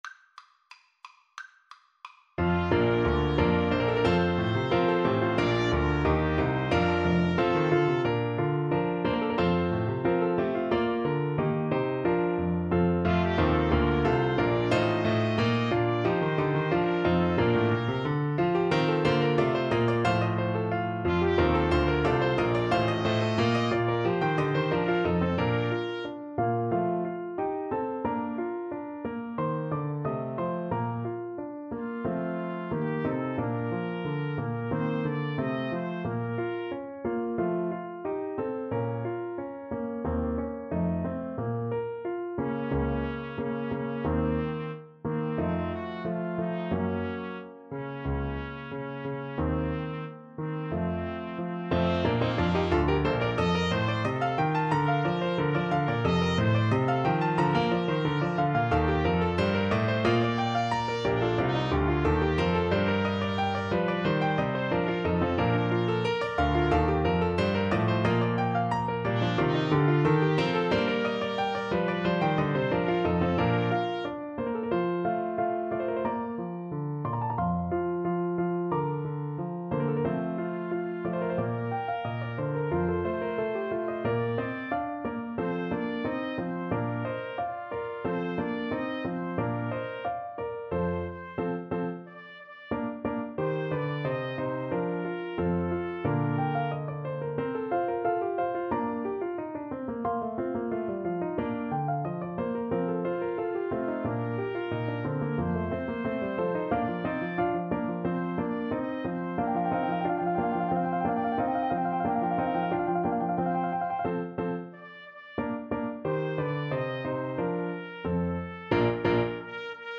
Free Sheet music for Trumpet-French Horn Duet
Bb major (Sounding Pitch) (View more Bb major Music for Trumpet-French Horn Duet )
Allegro =180 (View more music marked Allegro)
4/4 (View more 4/4 Music)
Classical (View more Classical Trumpet-French Horn Duet Music)